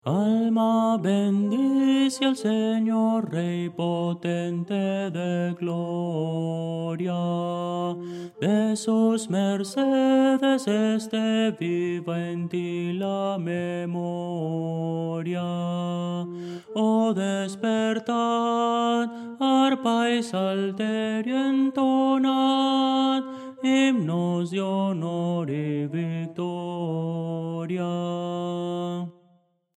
Voces para coro
Tenor – Descargar